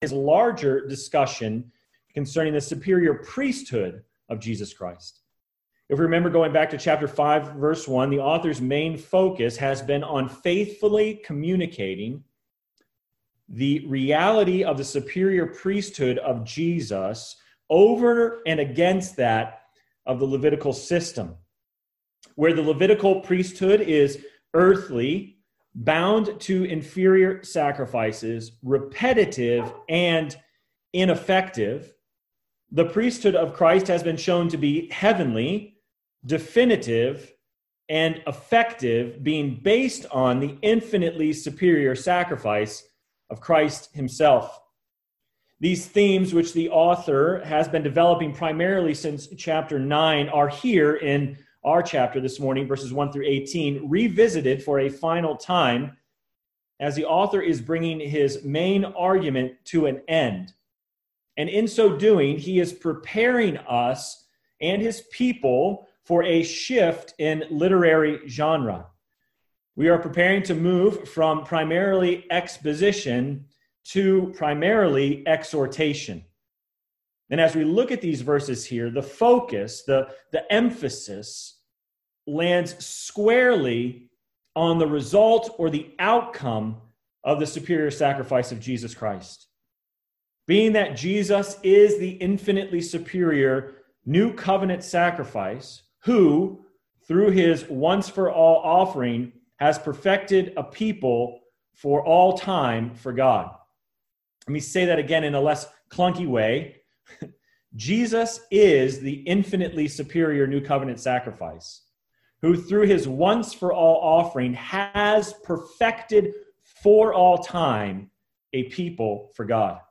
Sermon Text: Hebrews 10:1-18 First Reading: Psalm 40 Second Reading: Acts 26:1-18